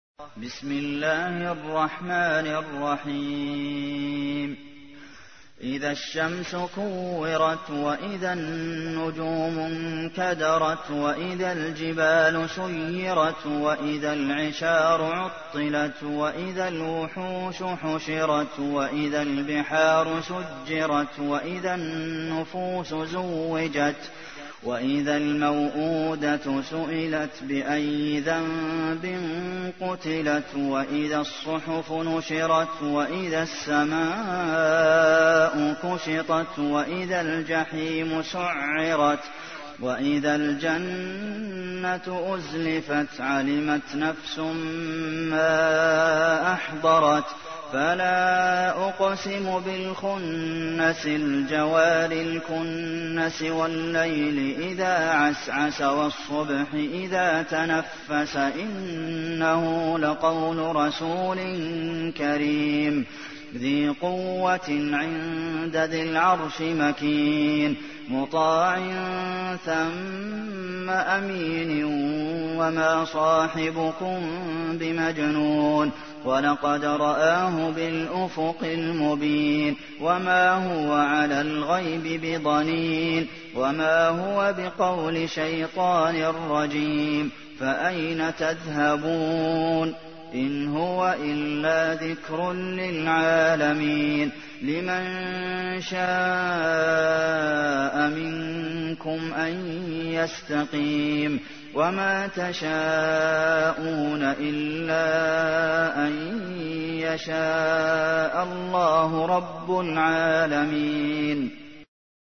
تحميل : 81. سورة التكوير / القارئ عبد المحسن قاسم / القرآن الكريم / موقع يا حسين